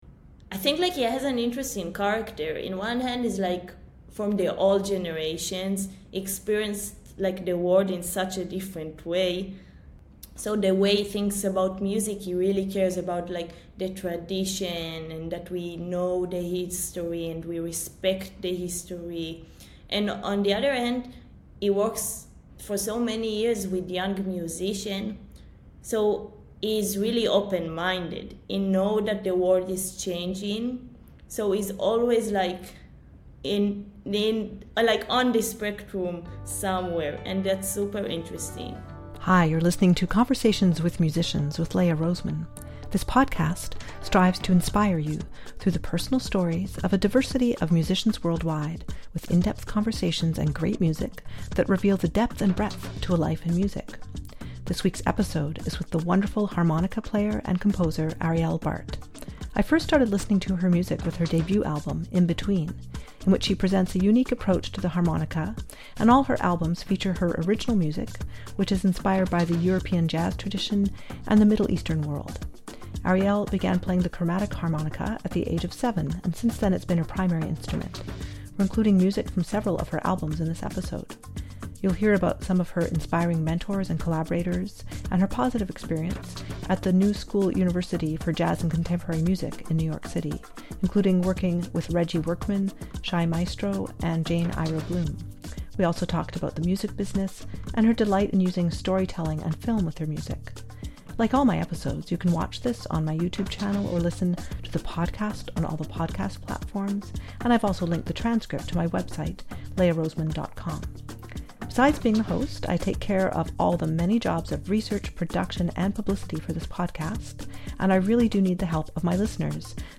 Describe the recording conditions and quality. This podcast strives to inspire you through the personal stories of a diversity of musicians worldwide, with in-depth conversations and great music, that reveal the depth and breadth to a life in music.